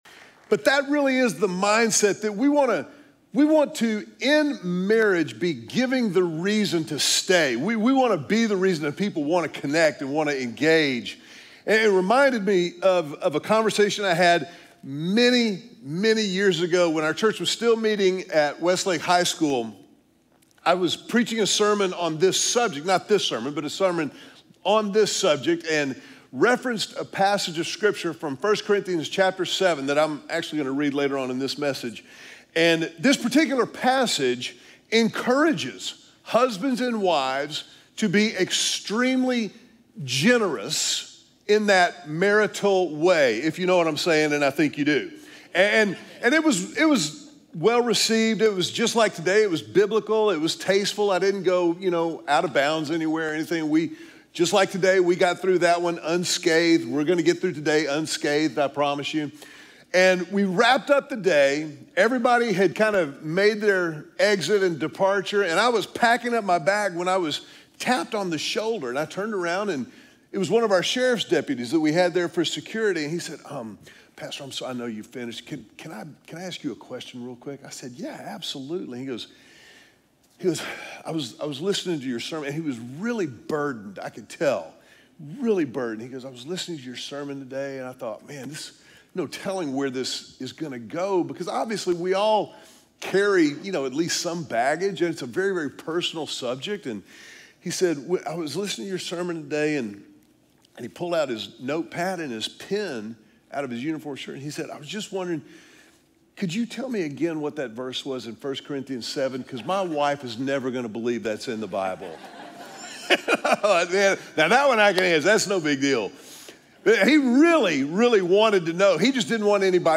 LHC_2nd_Service_1.mp3